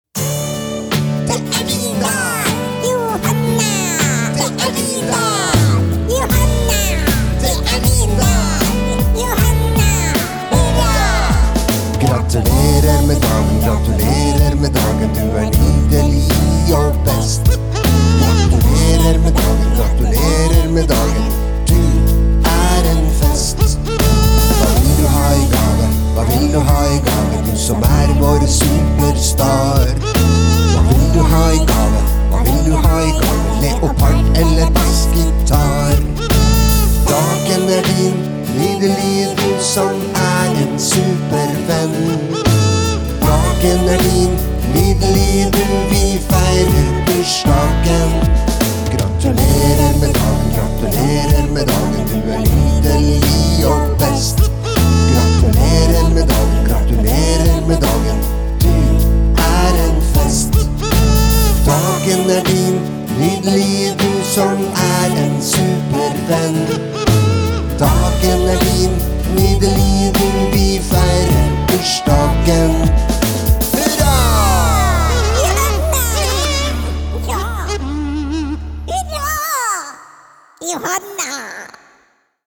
BARNESANG
Bra stemning i denne låta 🙂